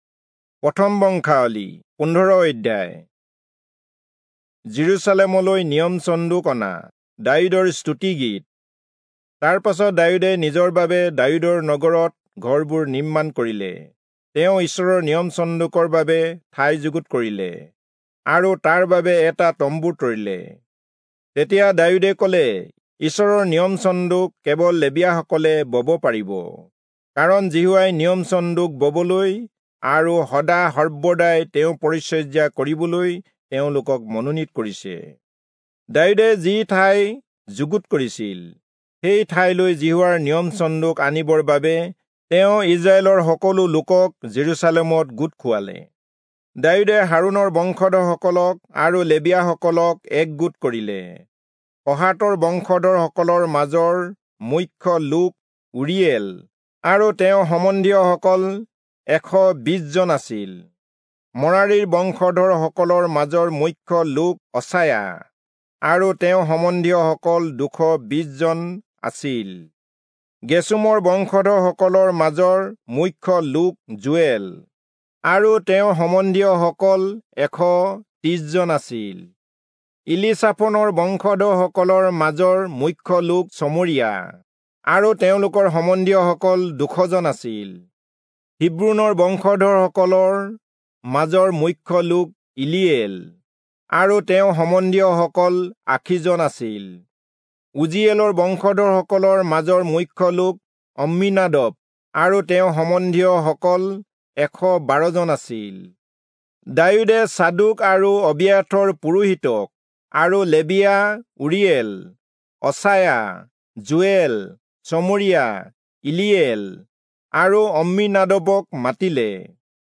Assamese Audio Bible - 1-Chronicles 13 in Ervmr bible version